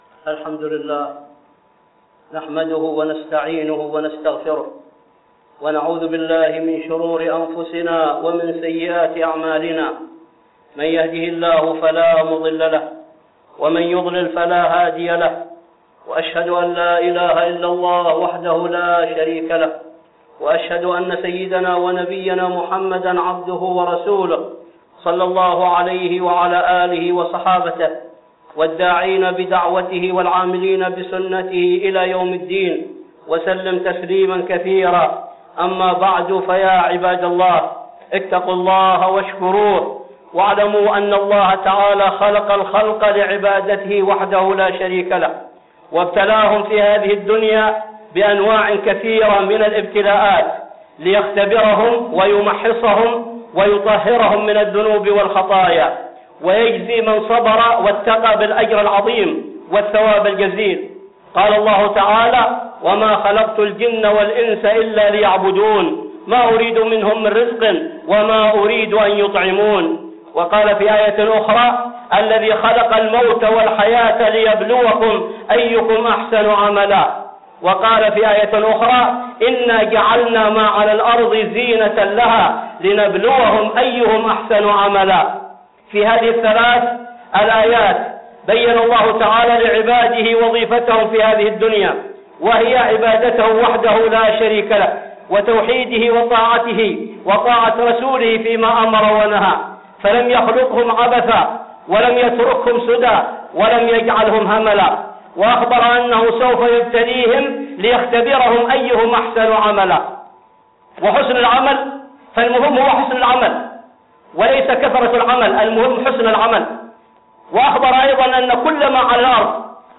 (خطبة جمعة) الابتلاء